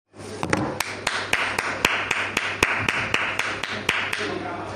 Clapping Clapping Sound Button - Free Download & Play